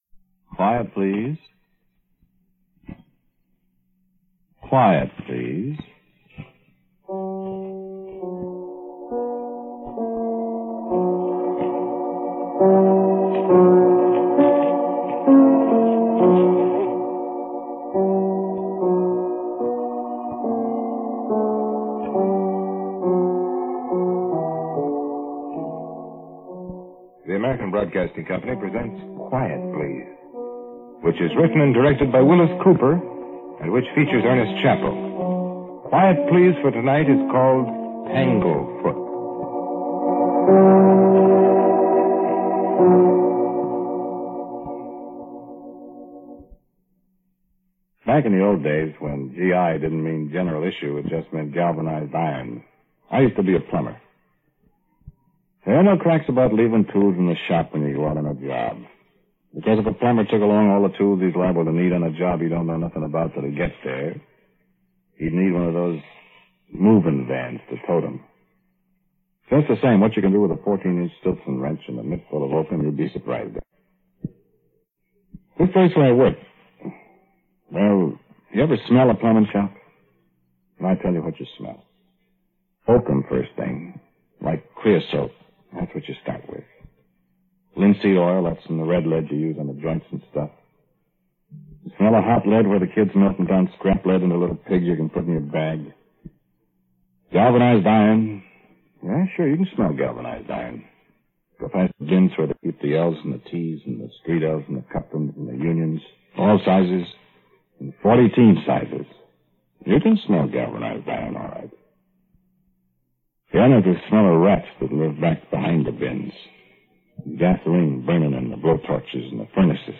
Noise-reduced version